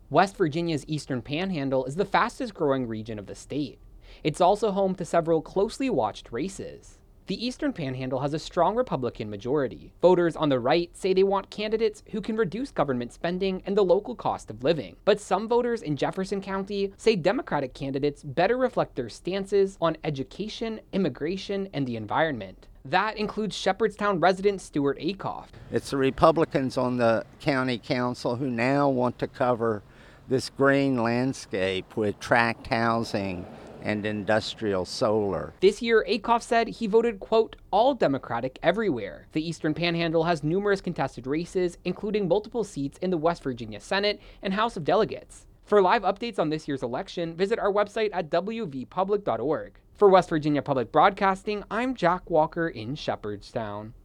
asks Jefferson County voters about local races